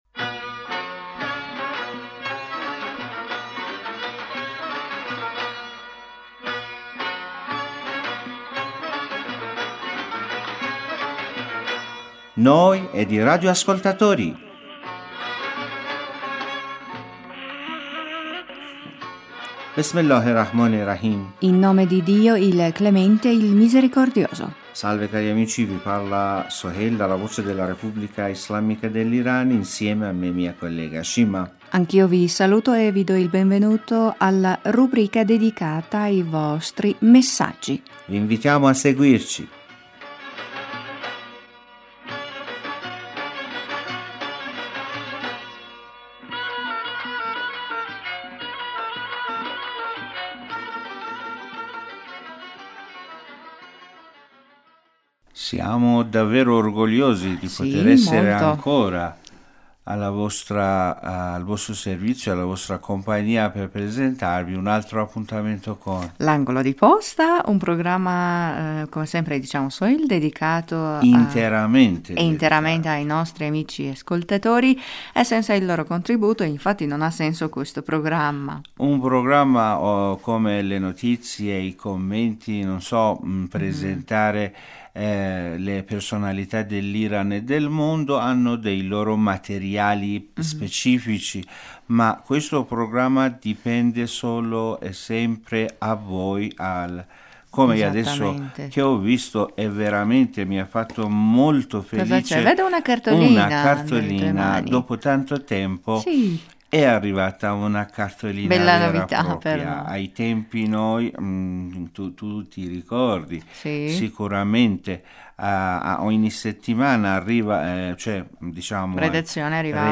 Vi invitiamo ad ascoltare anche una bella canzone persiana!